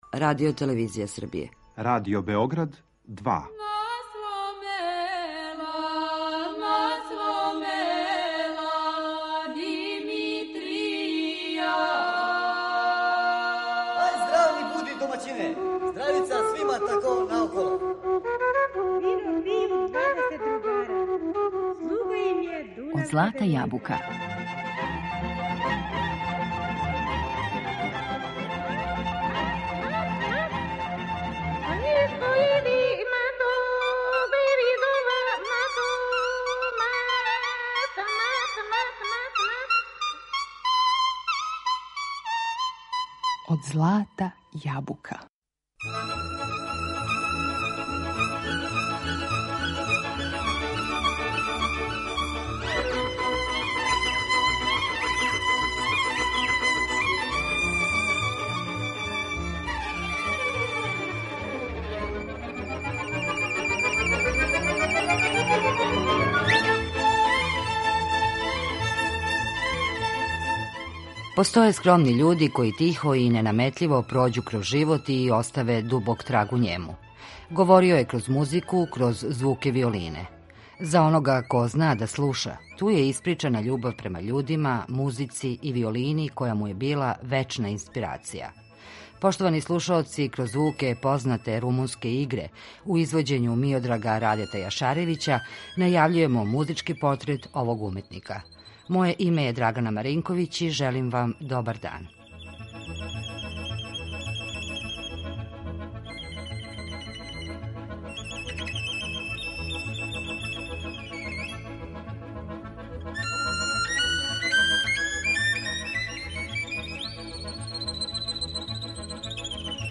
Његова виолина разликовала се од других по звуку, дивној мелодици и носталгичним тоновима.